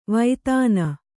♪ vaitāna